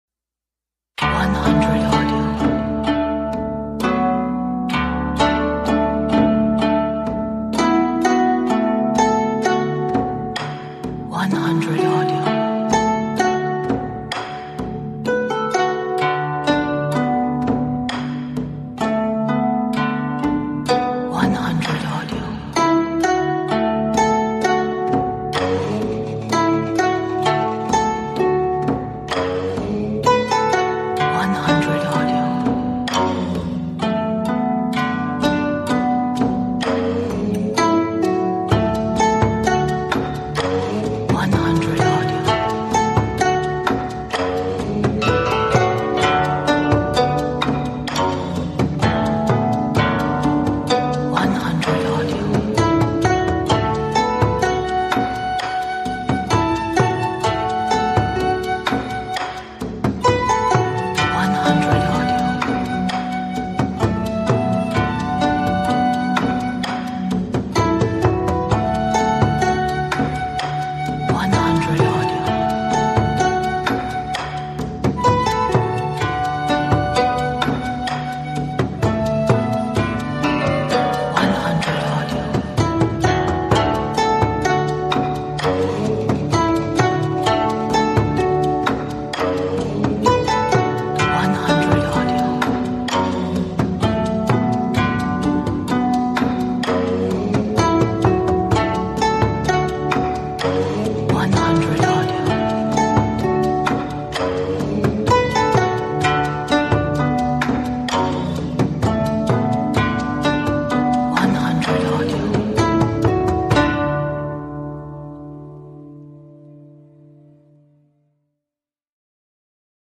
Antique background street view lantern banquet (2 Versions)